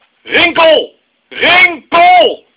rinkel.wav